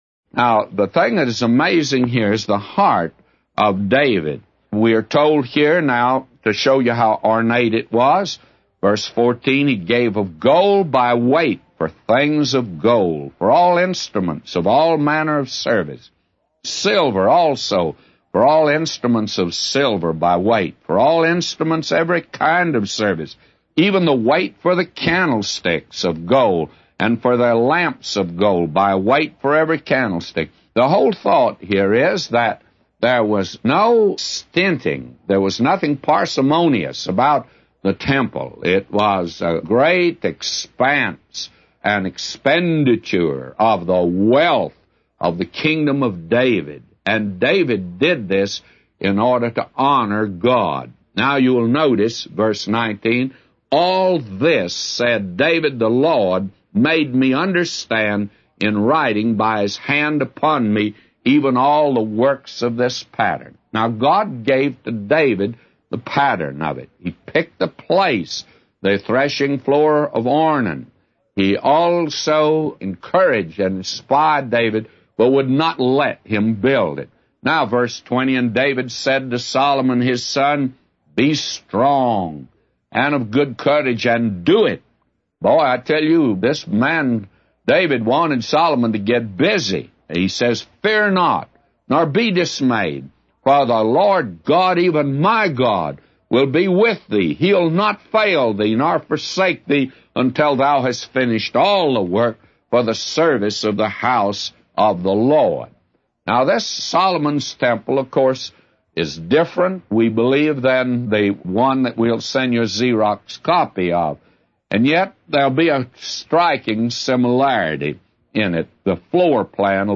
A Commentary By J Vernon MCgee For 1 Chronicles 28:14-999